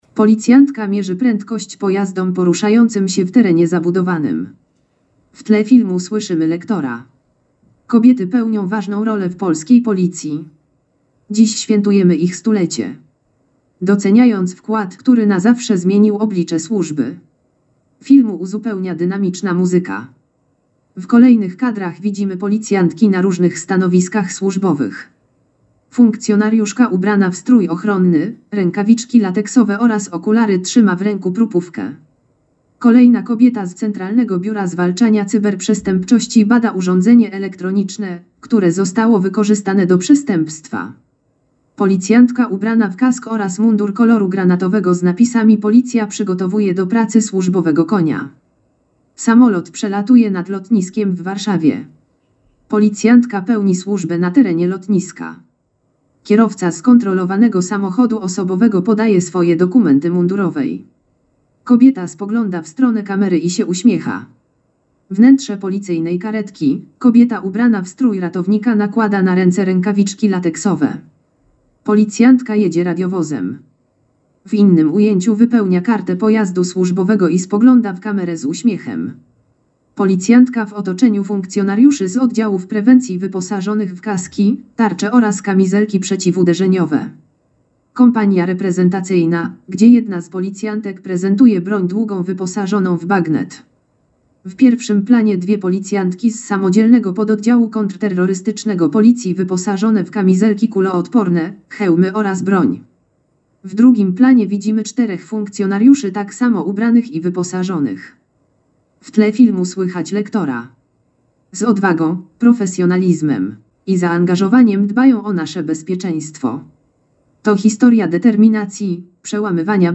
Nagranie audio Audiodeskrypcja filmu: Stulecie Kobiet w Policji.mp3